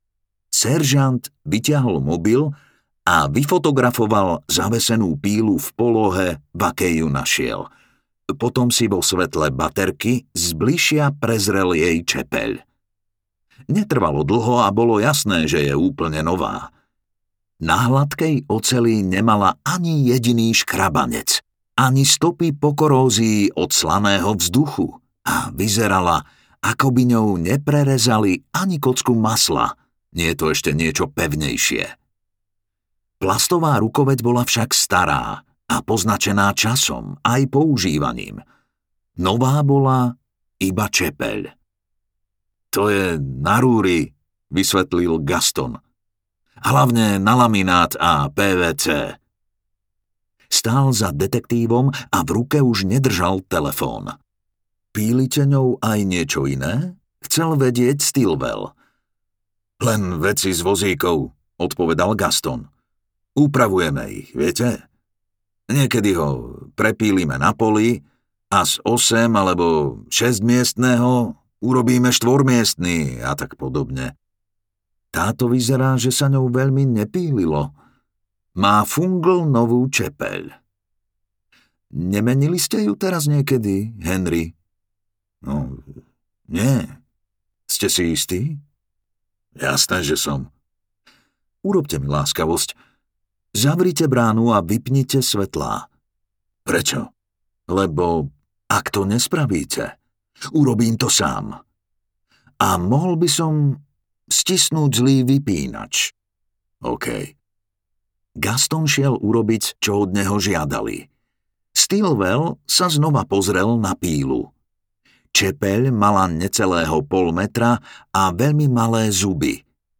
Temný kvet audiokniha
Ukázka z knihy